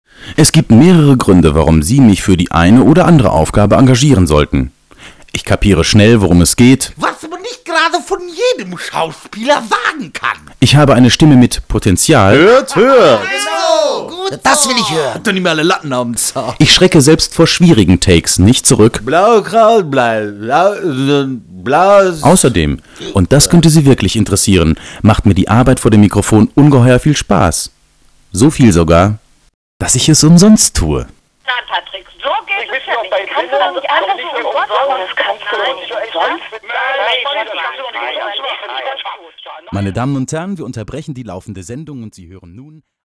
Sprechprobe: eLearning (Muttersprache):
german voice over artist